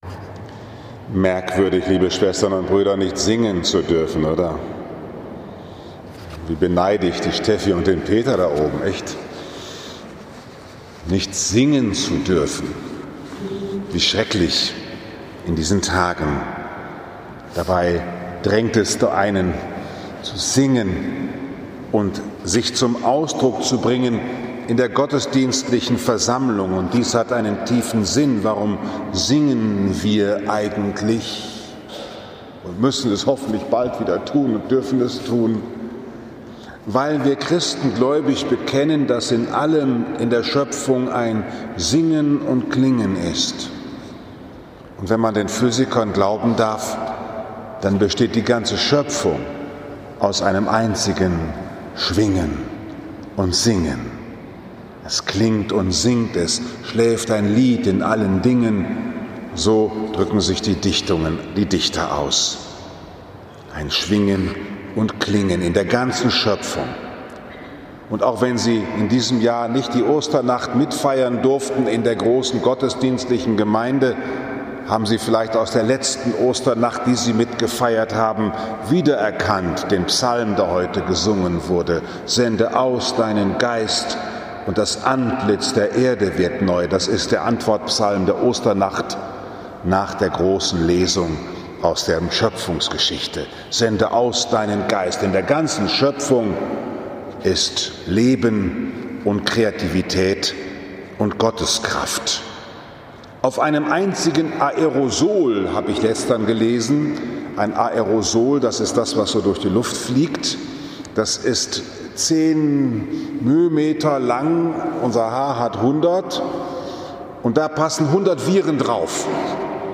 30. Mai 2020, Liebfrauenkirche Frankfurt am Main, Pfingstfest Lesejahr A